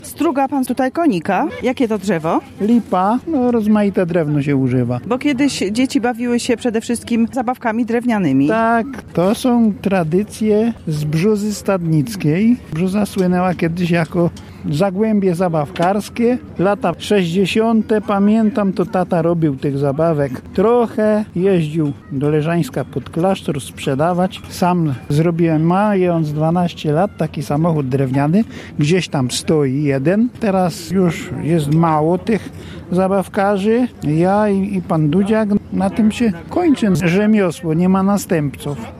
Fragment rozmowy z artystą, nagranej podczas ubiegłorocznego kiermaszu